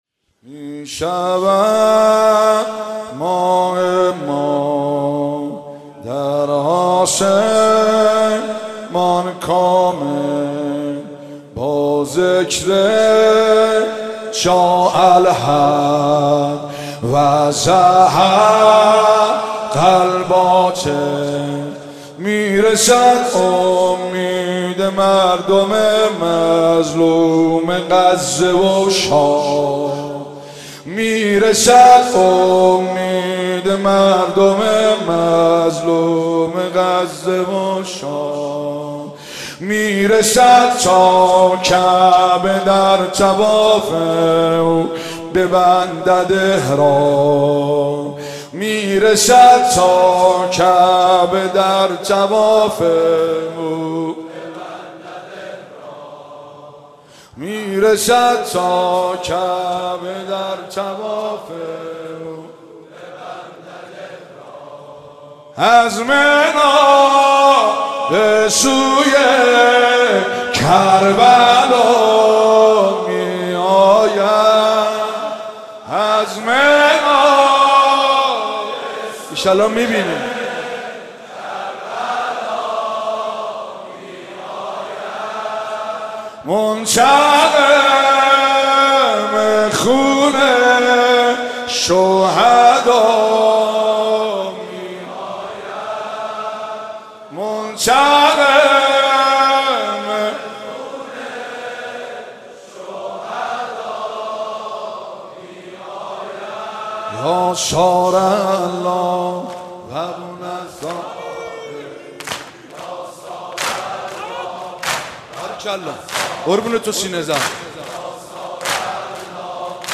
صوت مراسم شب سوم محرم ۱۴۳۷ هیئت فاطمیون ذیلاً می‌آید: